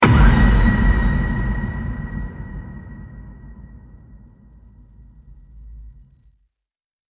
Br. Halloween Impact Sound Button - Free Download & Play